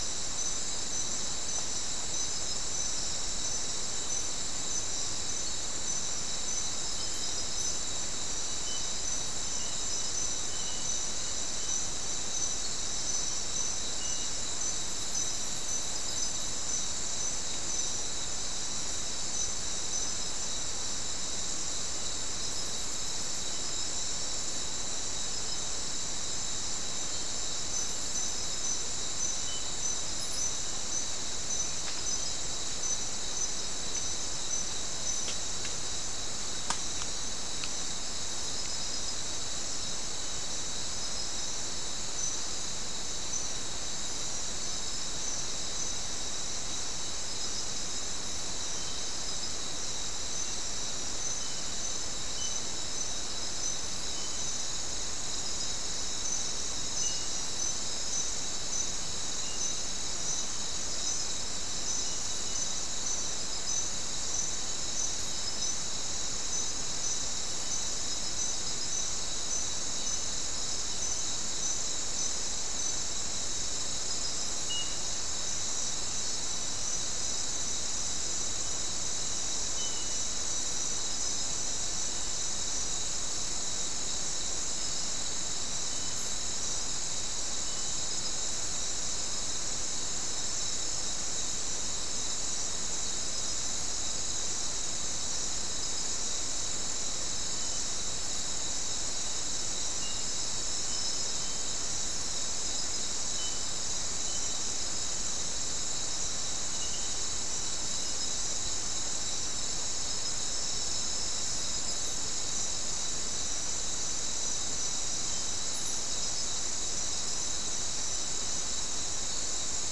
Soundscape Recording Location: South America: Guyana: Turtle Mountain: 2
Recorder: SM3